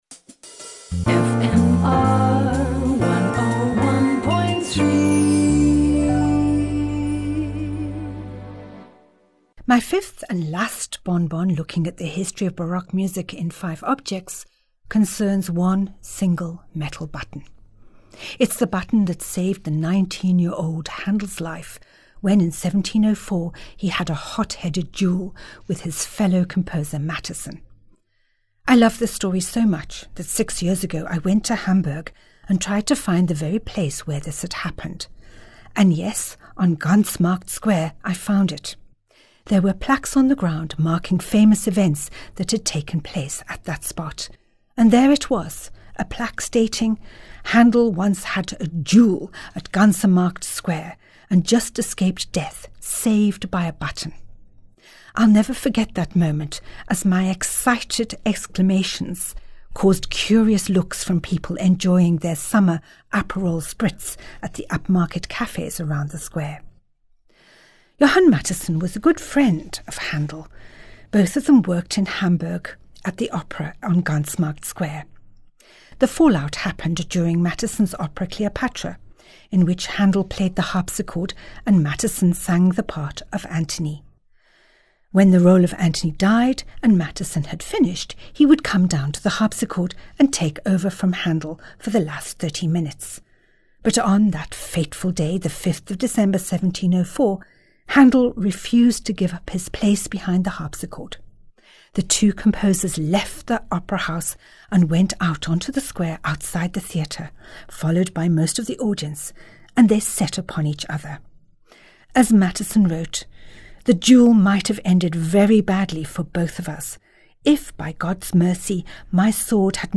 Each weekly Bon Bon is accompanied by a piece of Baroque music which ties in with the story.